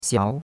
xiao2.mp3